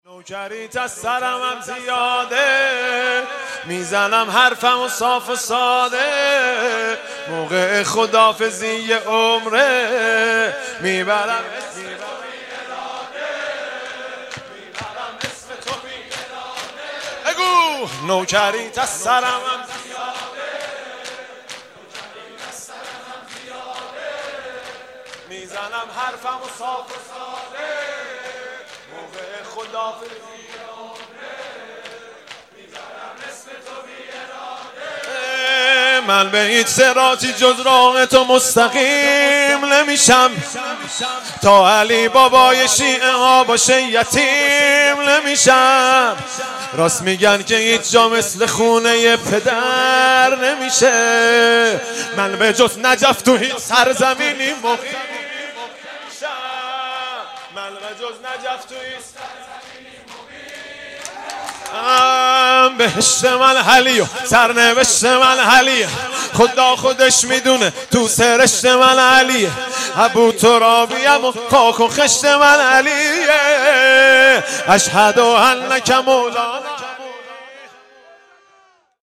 محرم 99 - شب هشتم - شور - نوکریت از سرمم زیاده